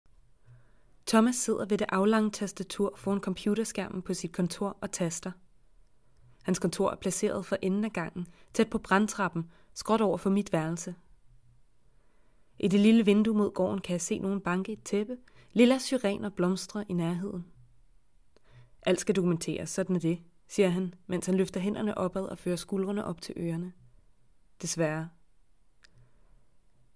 Danish, Scandinavian, Female, 20s-30s